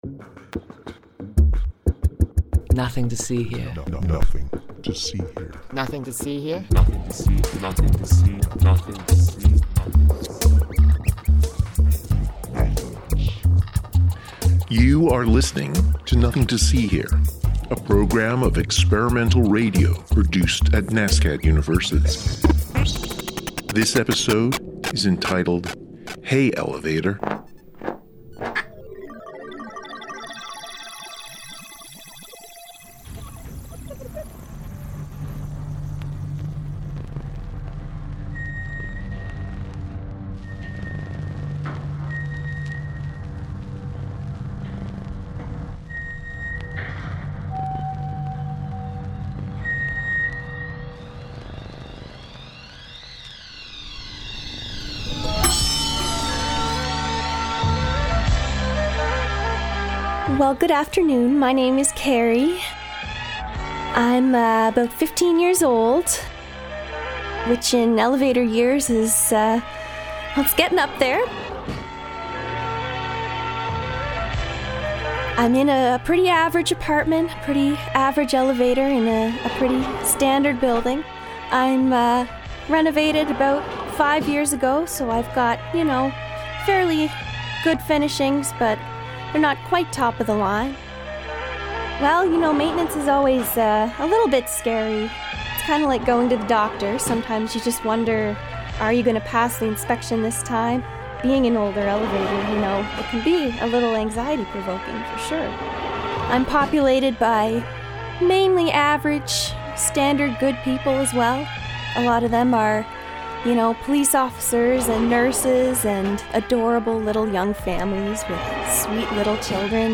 Sound Mixing and Sound Design